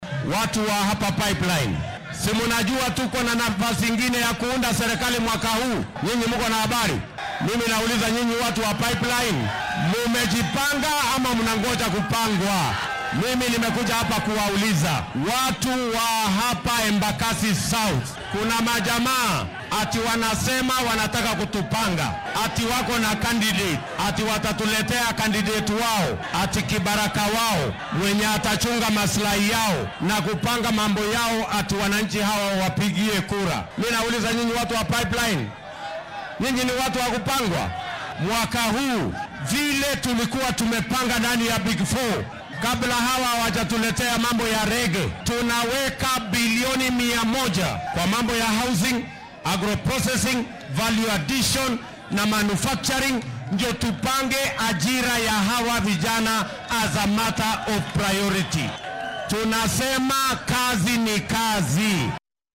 DHAGEYSO:William Ruto oo isku soo bax siyaasadeed ku qabtay Embakasi
Ku xigeenka madaxweynaha dalka William Ruto ayaa manta isku soo bax siyaasadeed ku qabtay xaafadda Pipeline ee koonfurta deegaanka Embakasi ee magaalada Nairobi.